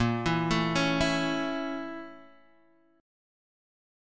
A/Bb chord